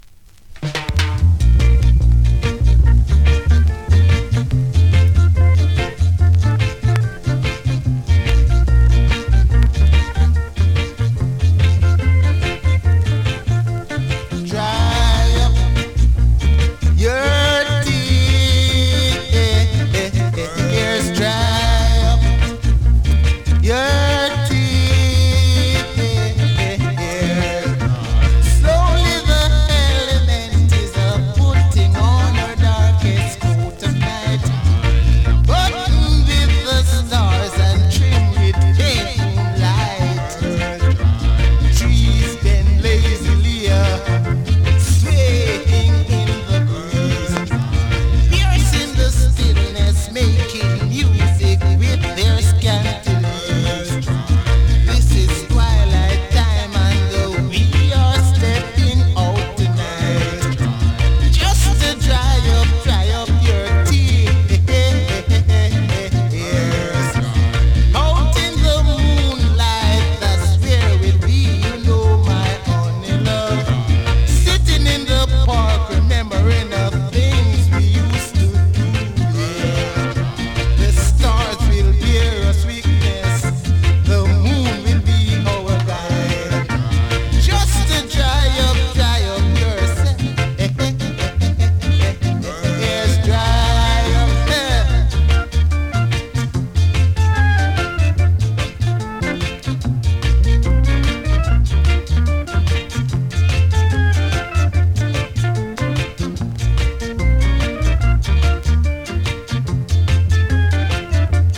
※キズ少し、プレイは良好
スリキズ、ノイズかなり少なめの